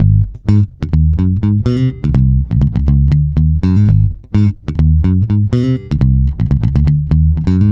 -MM BOOG G#.wav